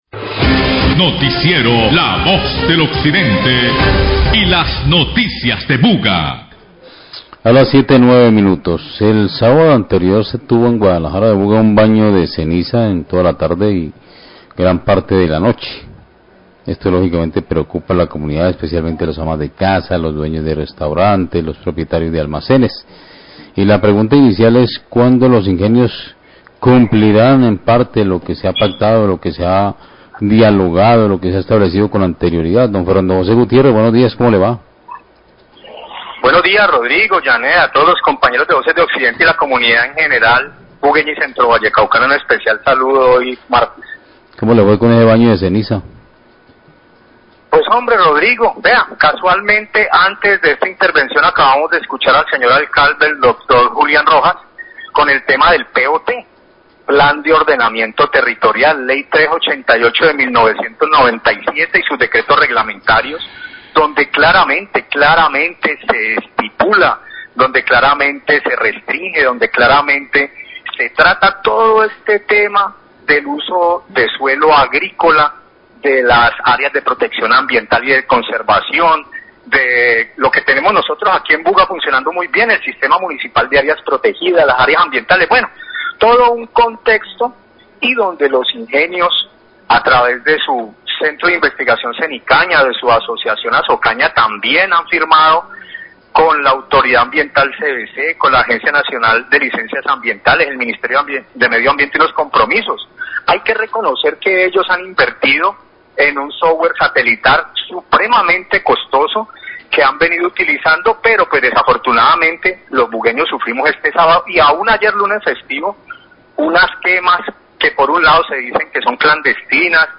Srio Agricultura Buga habla de contaminación por quema de caña
Radio